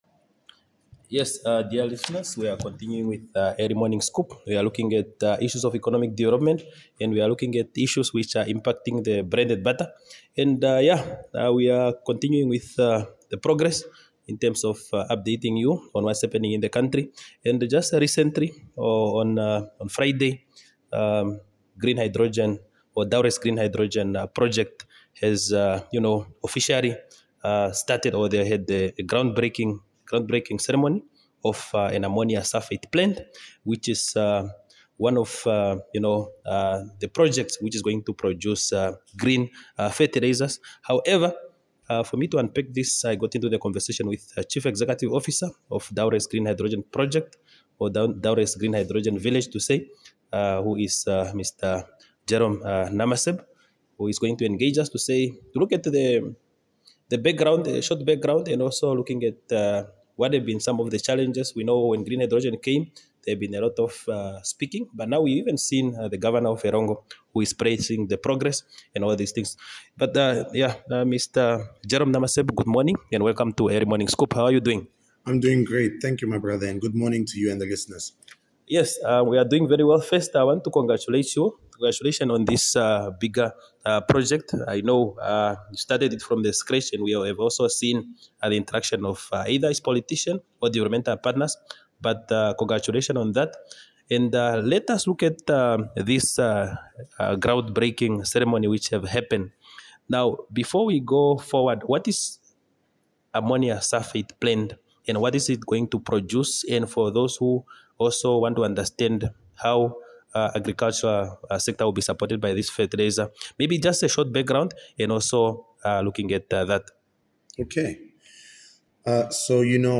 EARLY MORNING SCOOP INTERVIEW